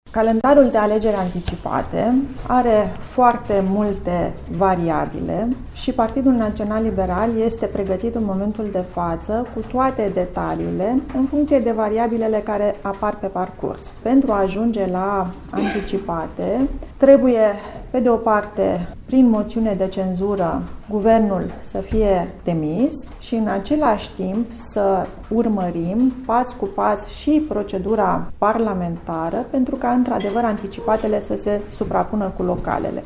Vicepremierul Raluca Turcan a reafirmat, ieri, la Sibiu, că liberalii sunt decişi să înceapă procedura pentru un scrutin înainte de termen şi a amintit care sunt paşii de urmat în această situaţie: